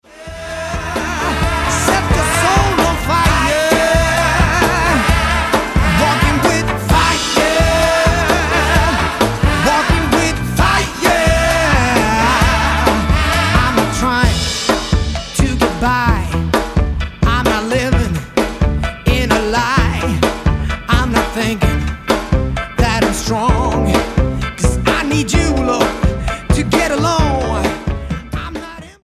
Gospel Album
Style: Pop